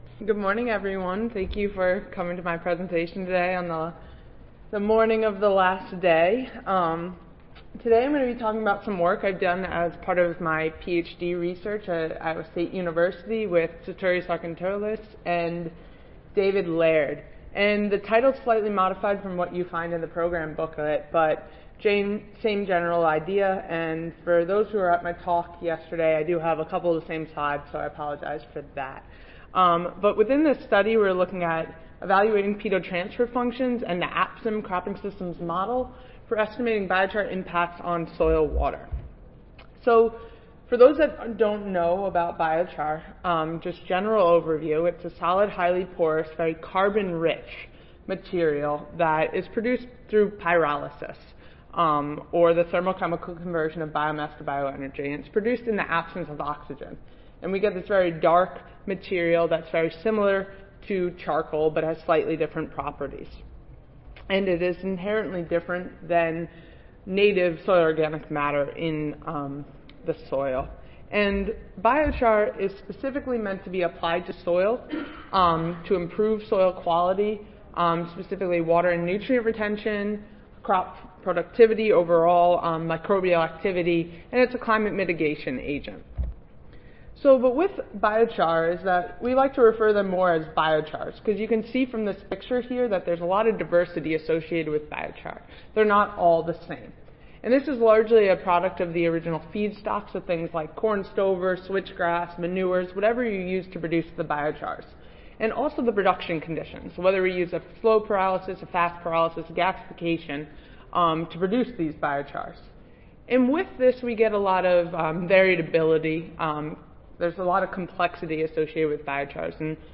Iowa State University Audio File Recorded Presentation